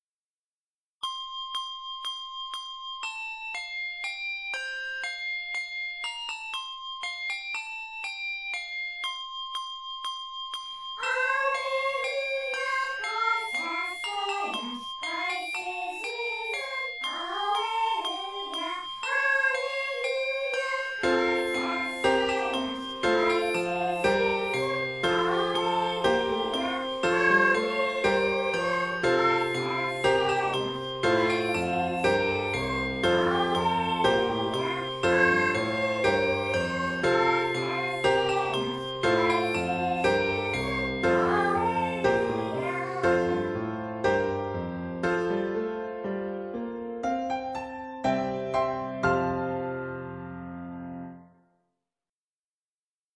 handbells, and keyboard.